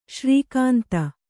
♪ śrī kānta